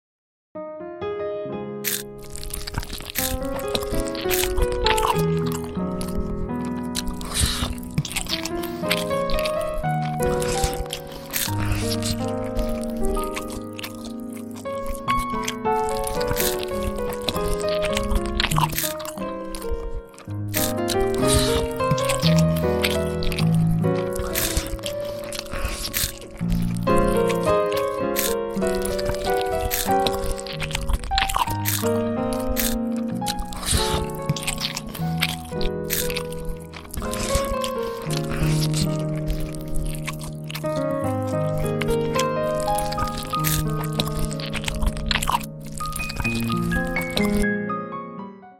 Asmr Mukbang Animation _ Asmr Eating Sounds